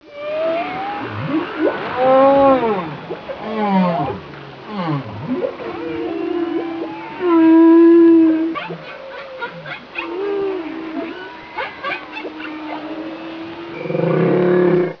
WHALES.WAV